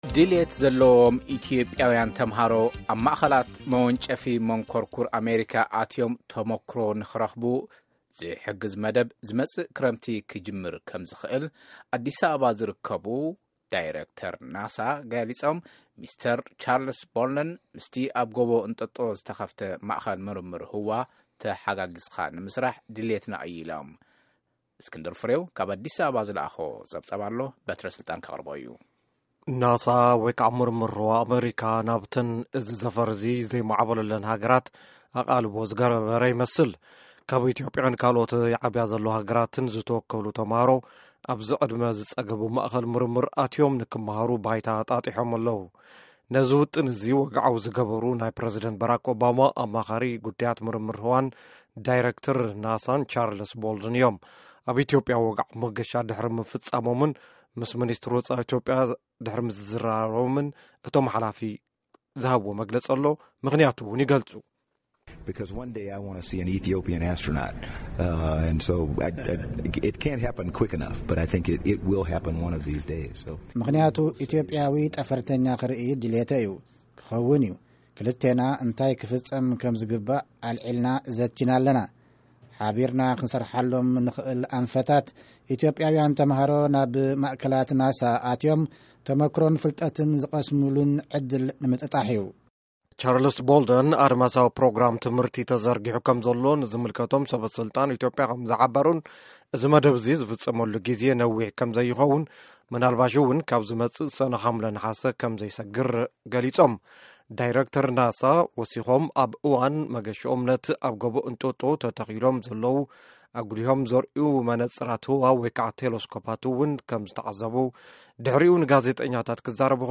ሙሉእ ሪፖርት